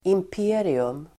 Uttal: [imp'e:rium]